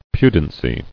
[pu·den·cy]